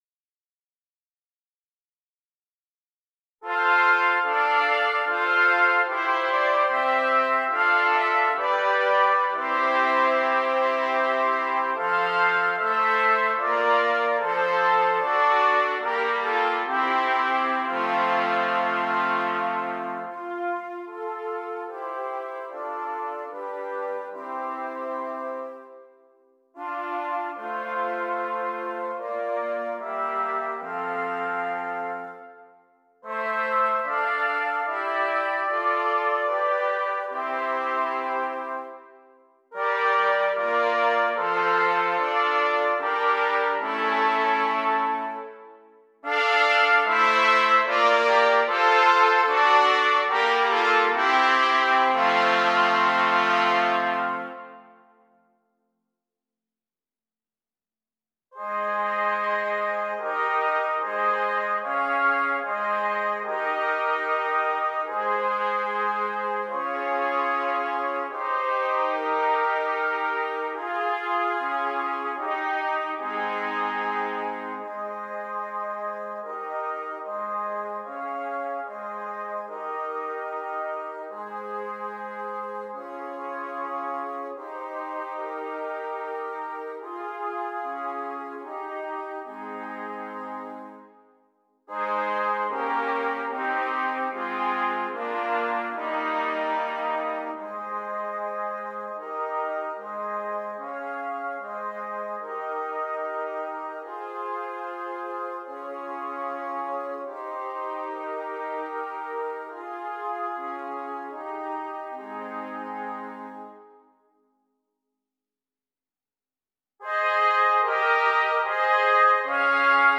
Brass
4 Trumpets